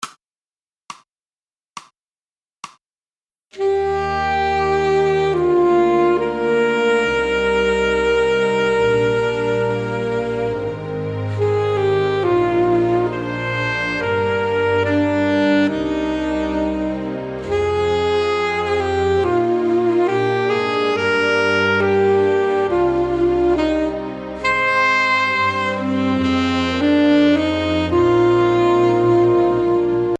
Voicing: Alto Saxophone Collection